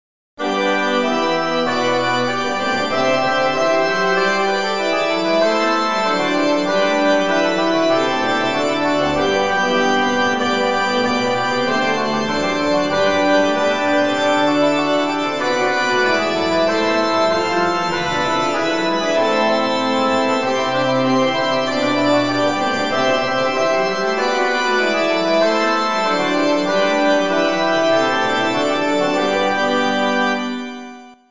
Midi Tr-Ob-Hr-Trb-Org